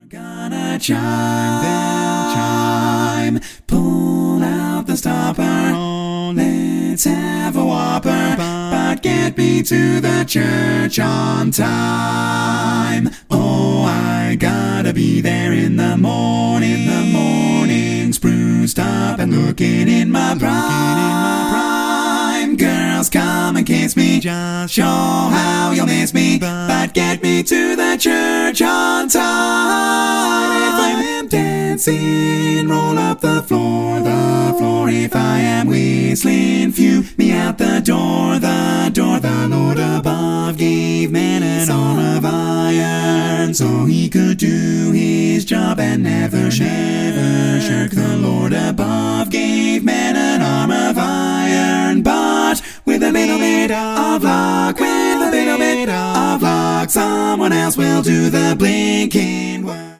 Full mix only
Category: Male